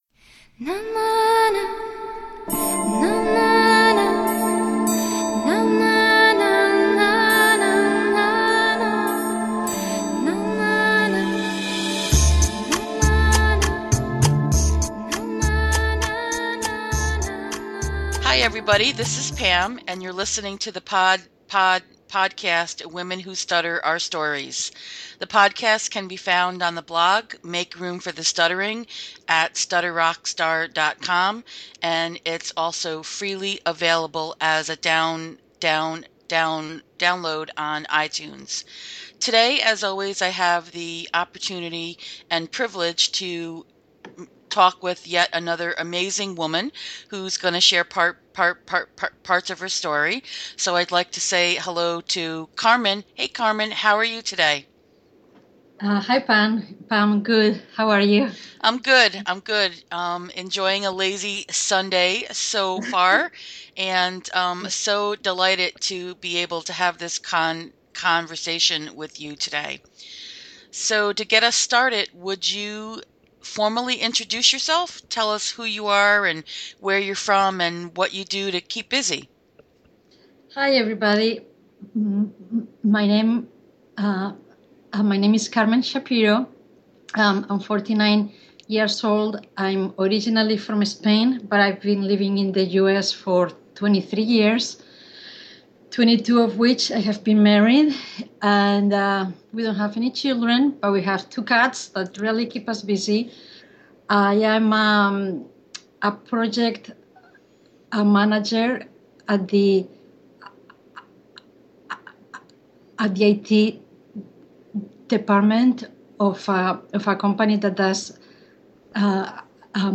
This was a wonderful and insightful conversation.